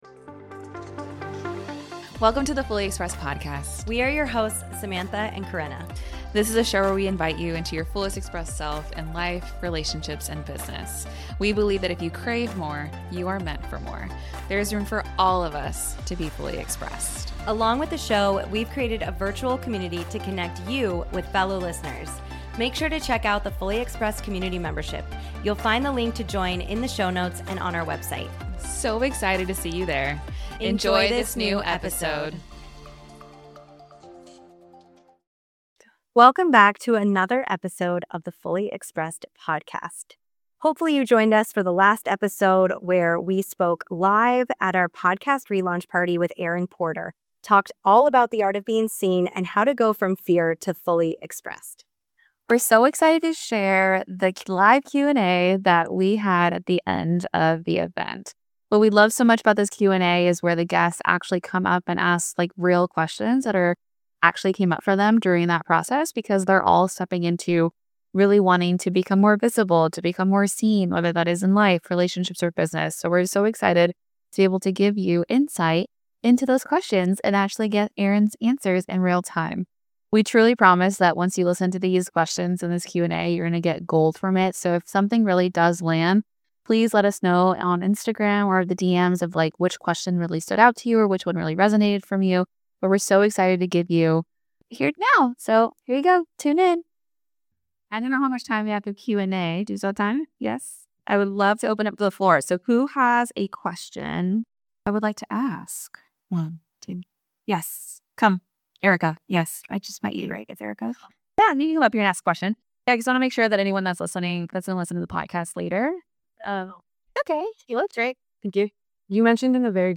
Live Podcast Recording